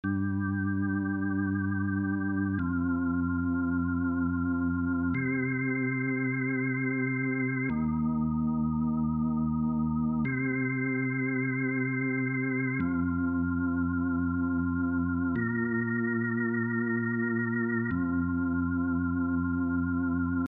La partie commune joue un note tous les 4 temps :